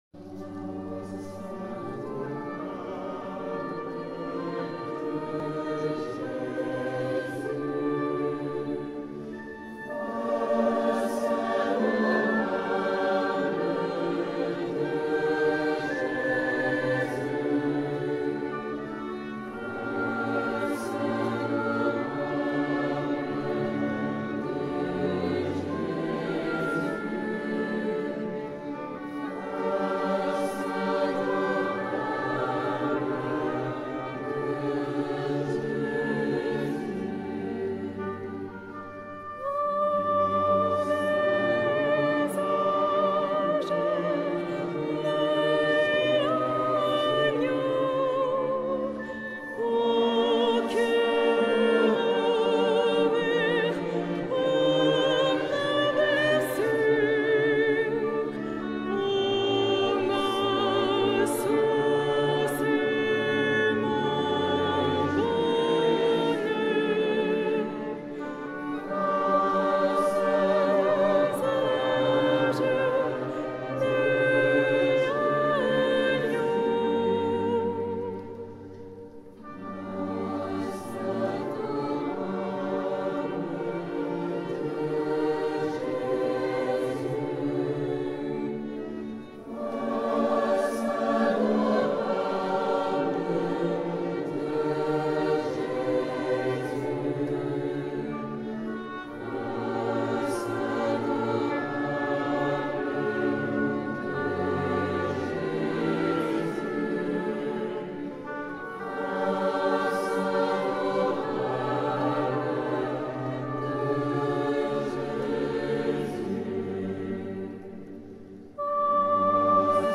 Chants de veillée et de louange
Pour écouter Pour télécharger le fichier audio : "Face adorable de Jésus" 17 novembre 2018 Choeur de la messe des étudiants 2018 à Notre dame de Paris